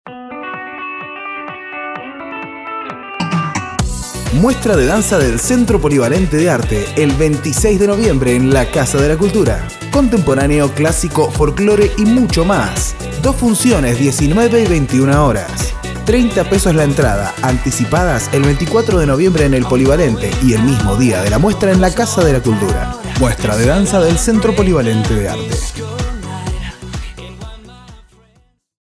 locutor argentino voz juvenil y muy versatil de tono medio a grave
Sprechprobe: Sonstiges (Muttersprache):